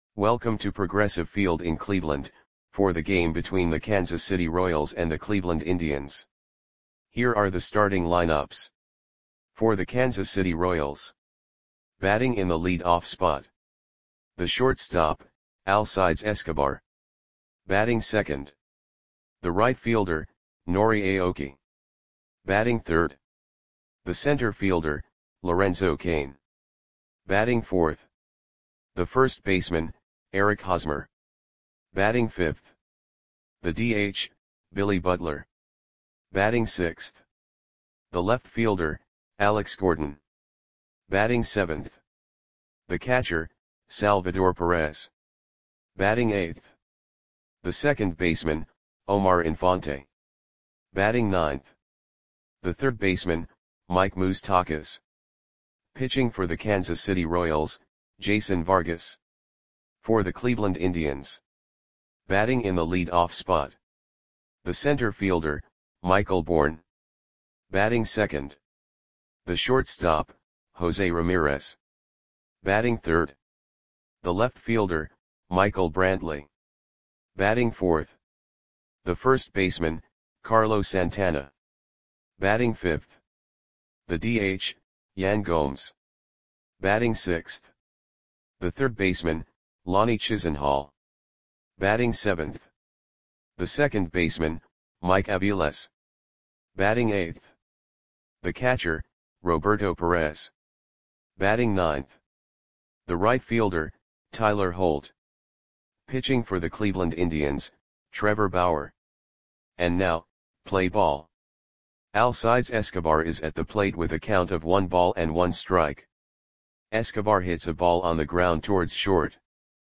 Audio Play-by-Play for Cleveland Indians on September 24, 2014